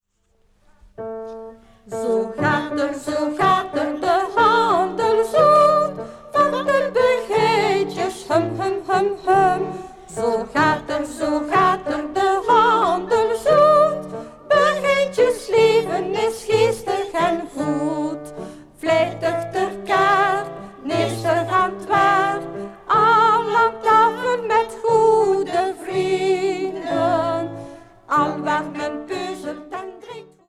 begijnen van het Groot Begijnhof van Sint-Amandsberg
Klavier en solozang:
Studio G.S.T. Gent.